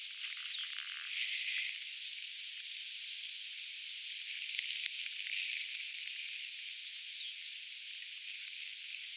tuollaisia tjy-ääniä kuulee
tuollaisia_tjy-aania_kuulee_joskus_pajusirkkulinnulta.mp3